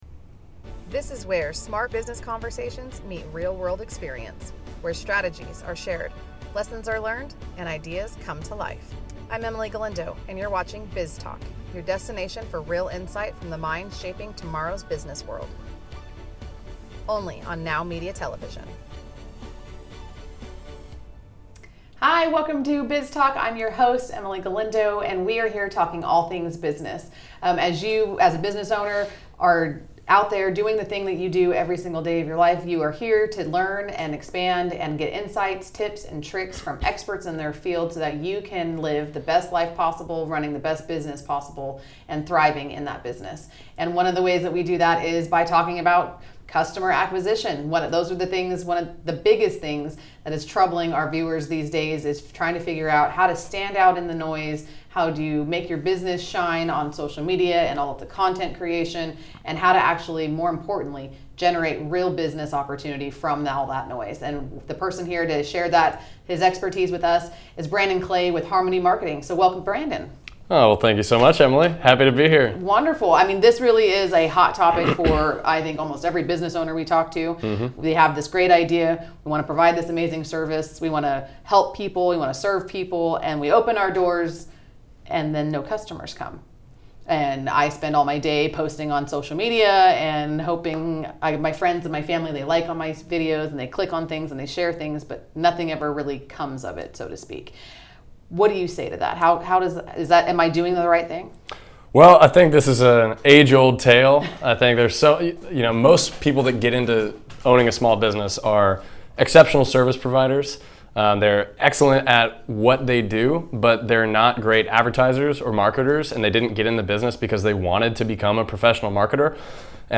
four powerful conversations to strengthen your business and lifestyle